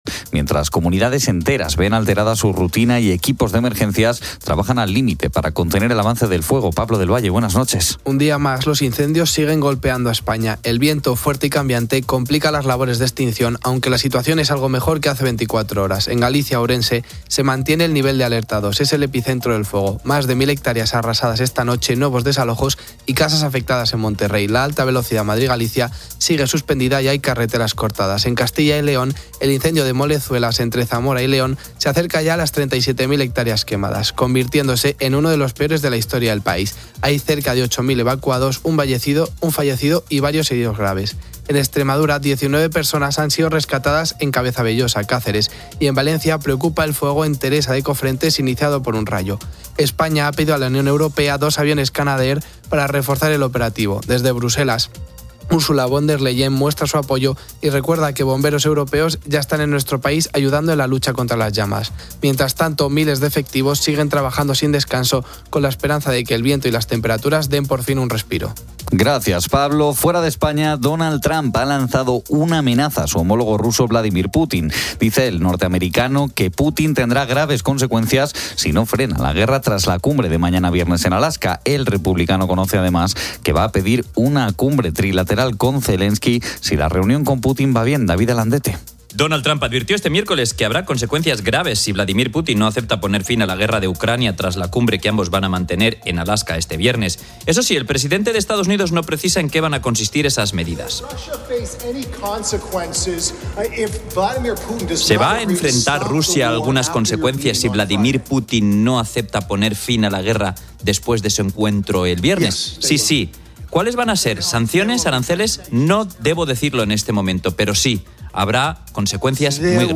En el programa "Poniendo las calles", se habla de fiestas patronales y se entrevista a una profesora sobre el uso de la música en el aula. También se aborda la piromanía y el apoyo psicológico a las víctimas.